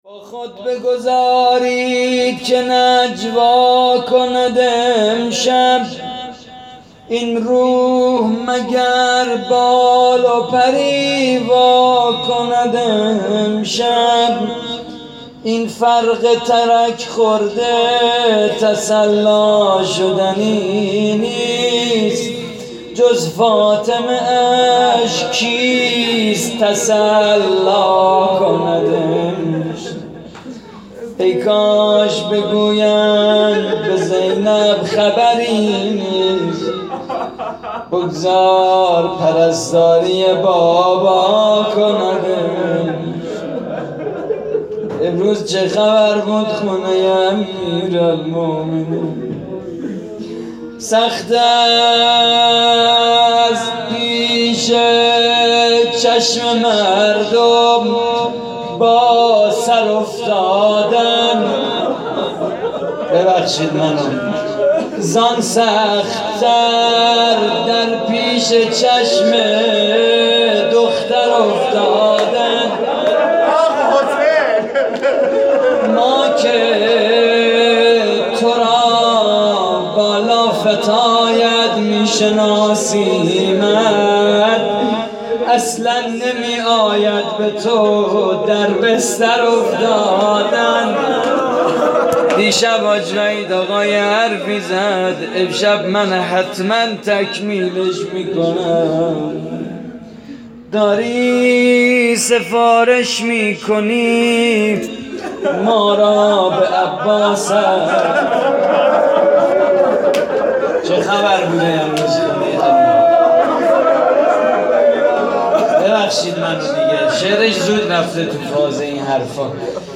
روضه خانگی شهادت حضرت امیر (ع) / هیئت محبین امیرالمؤمنین (ع) - 27 خرداد 96